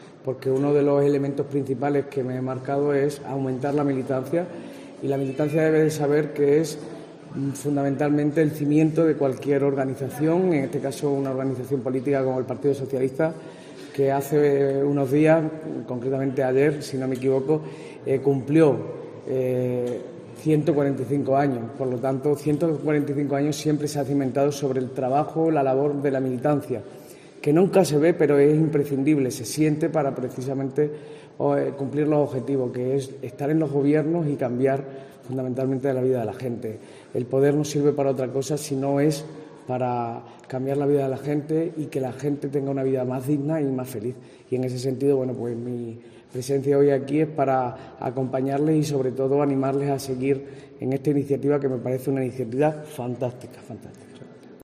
Miguel Ángel Gallardo se ha pronunciado de esta forma en Fuente del Maestre, donde ha asistido a un acto con militantes organizado por la agrupación local del PSOE, a la que ha agradecido esta iniciativa, ya que "hay que premiar también el trabajo y la labor de la militancia".